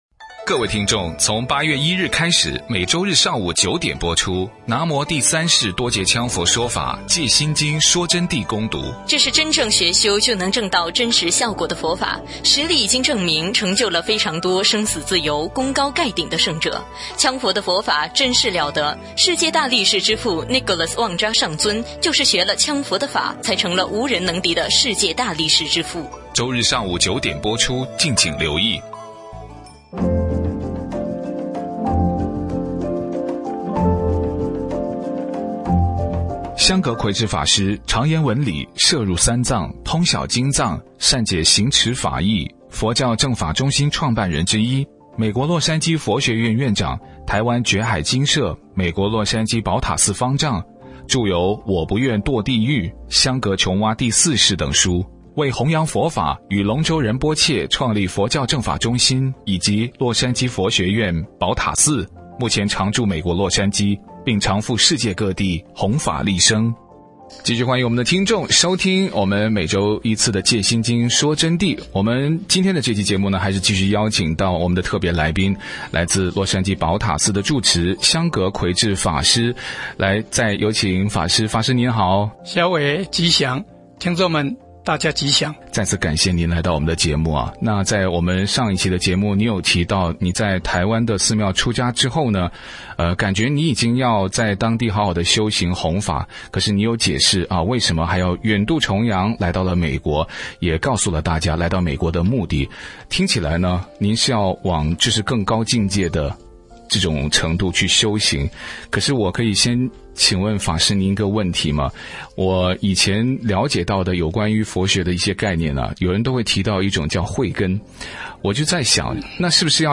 佛弟子访谈（二）什么是慧根善根？南无羌佛佛法与常规佛法的区别？